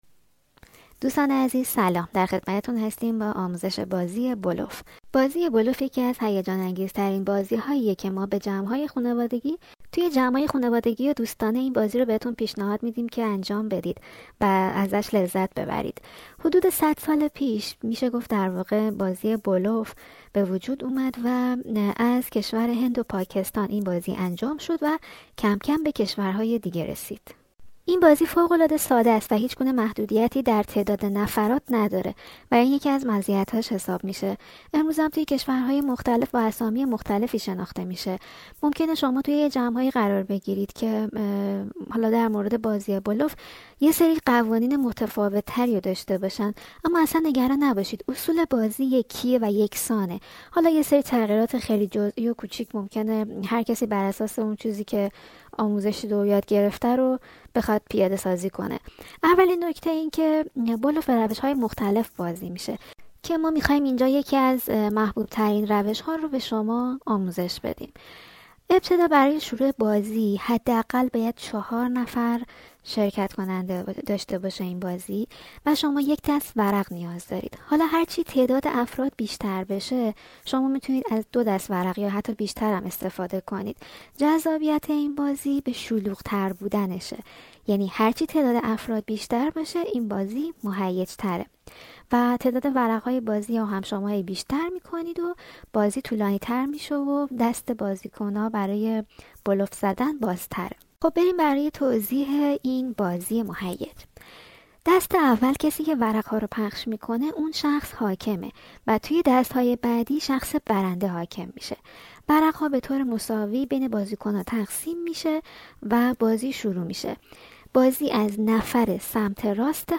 پادکست آموزش بلوف